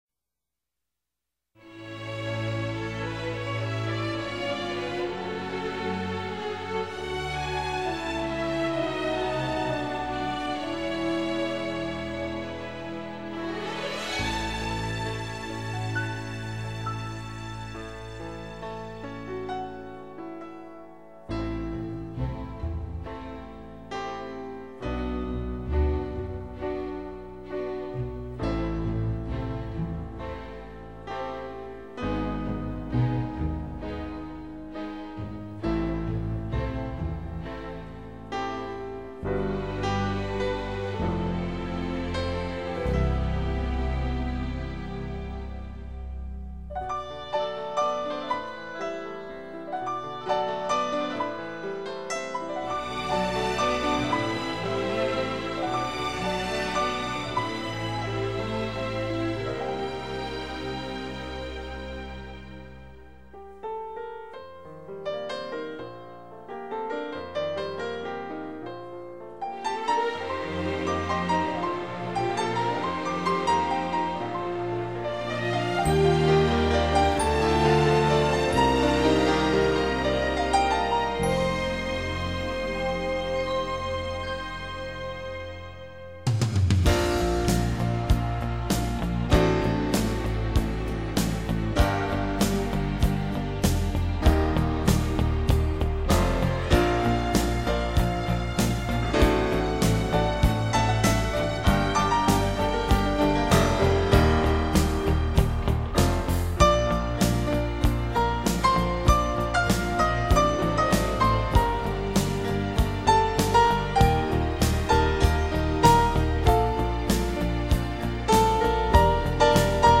手指微妙触及琴键所散发出的音符，充满罗曼蒂克式的醉人芳香将你的情思带入神话中的伊甸园。